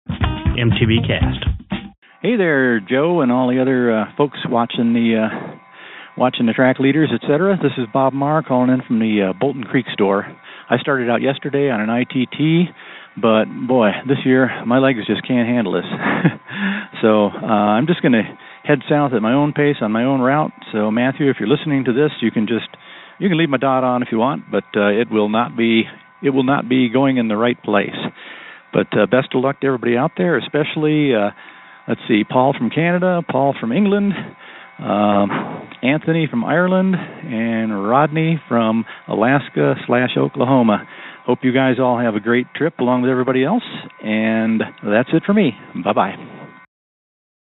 Posted in Calls , TD18 Tagged bikepacking , cycling , MTBCast , TD18 , ultrasport permalink